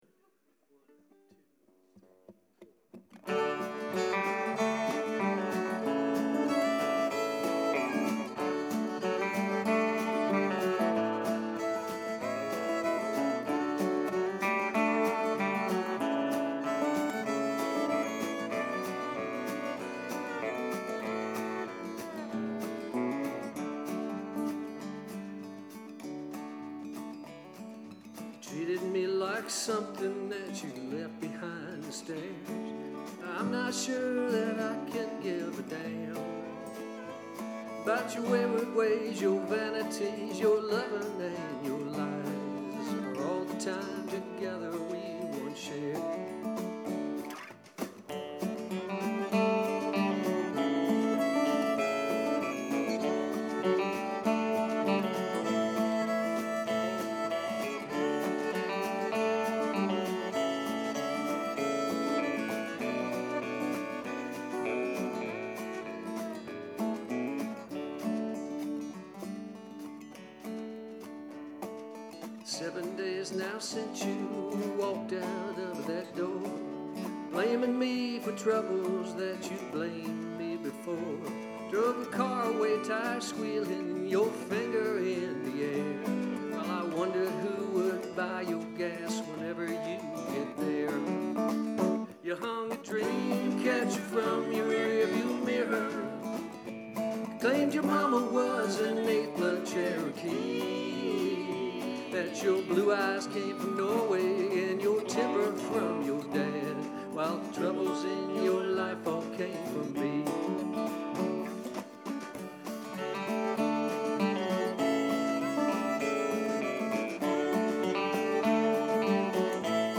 This is our debut performance.
Two hour long sets with a rapt and appreciative audience.